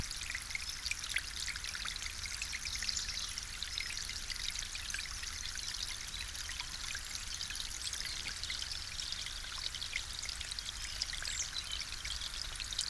На этой странице вы можете слушать онлайн или бесплатно скачать успокаивающие записи журчания свежей ключевой воды.
Шепот плавной струи в природе